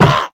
Minecraft Version Minecraft Version 25w18a Latest Release | Latest Snapshot 25w18a / assets / minecraft / sounds / entity / shulker / hurt3.ogg Compare With Compare With Latest Release | Latest Snapshot
hurt3.ogg